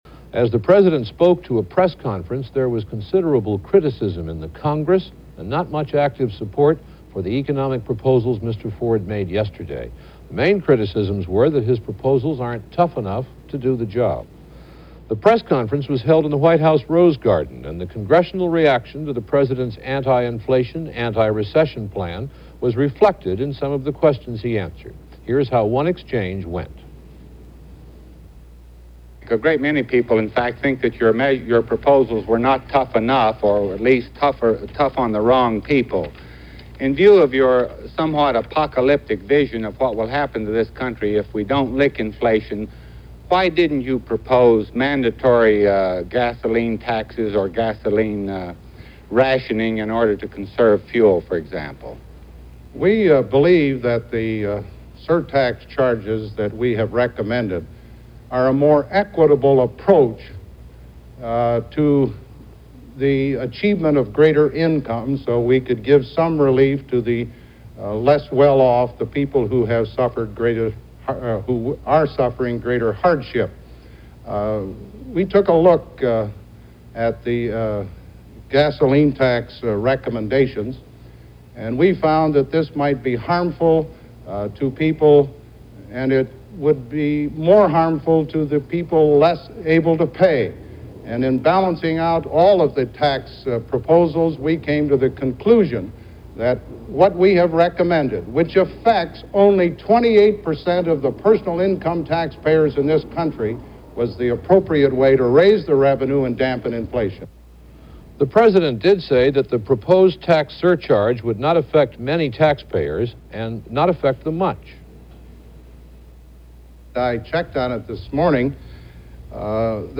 NBC-Nightly-News-October-9-1974.mp3